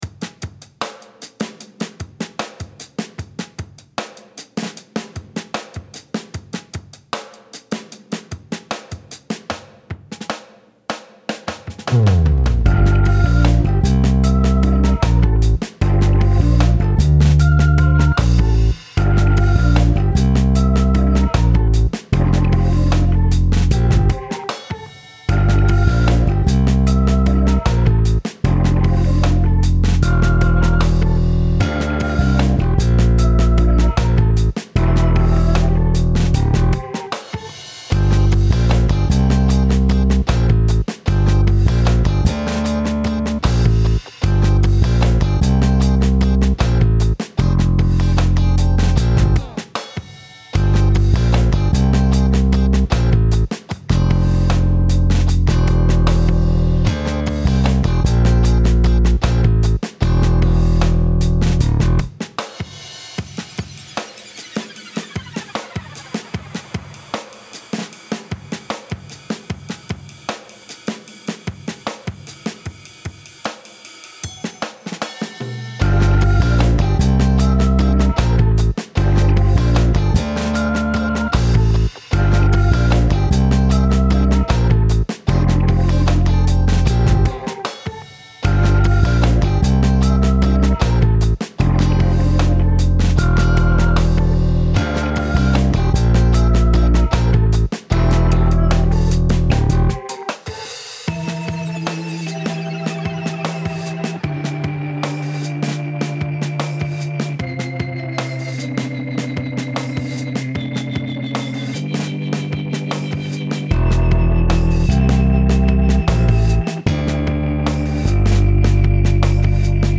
Style Style Oldies, Other
Mood Mood Cool, Dark, Relaxed
Featured Featured Bass, Electric Guitar, Organ
BPM BPM 76